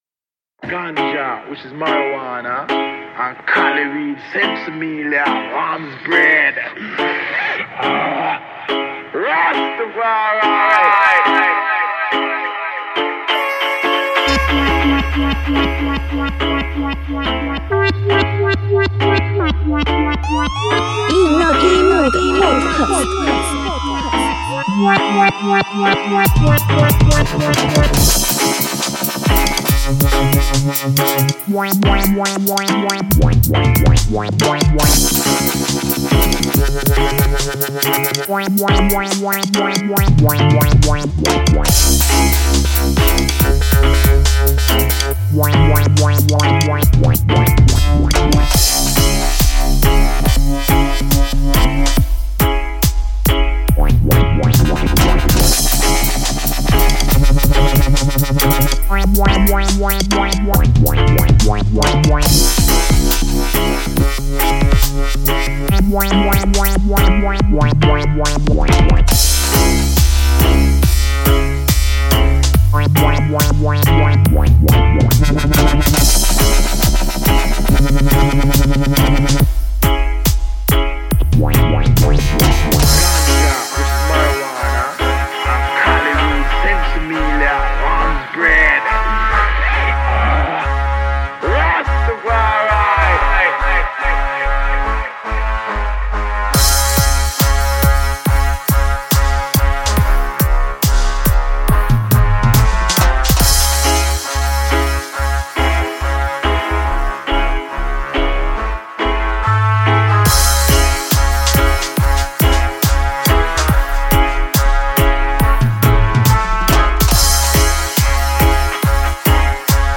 A tasty Reggae Dubstep podcast